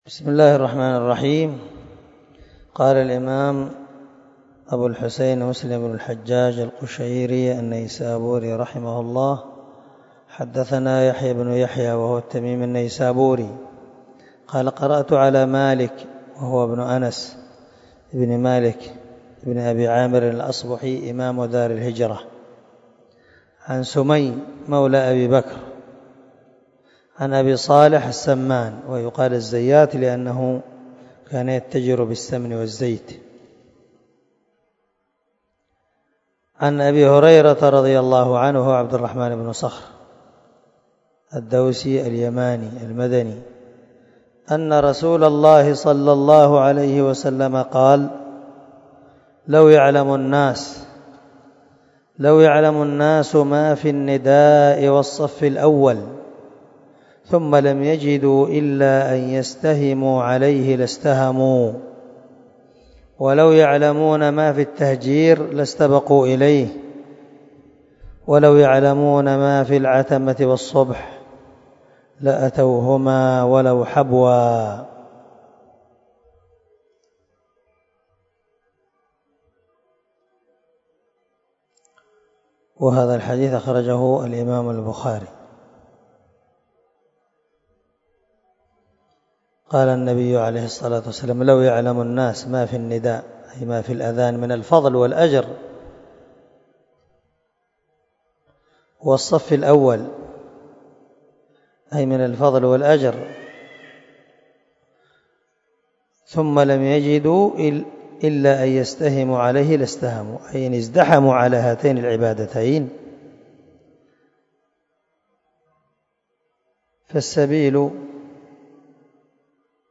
298الدرس 42 من شرح كتاب الصلاة حديث رقم ( 437 - 439 ) من صحيح مسلم